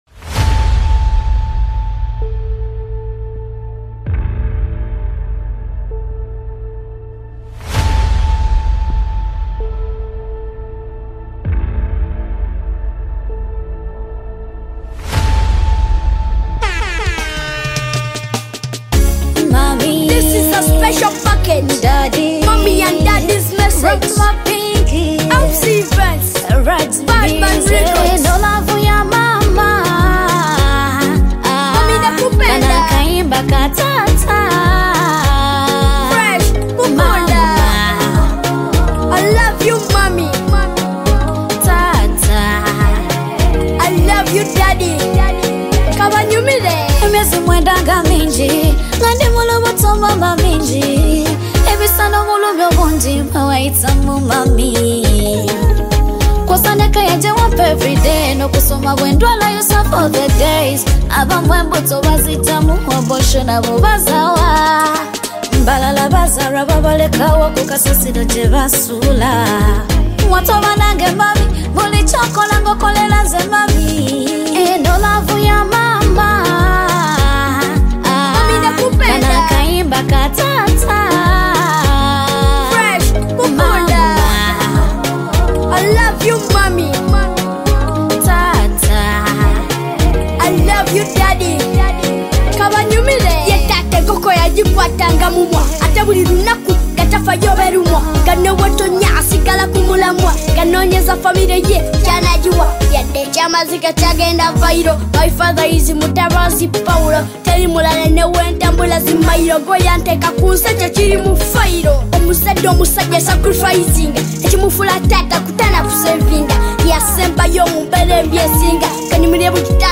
Genre: RNB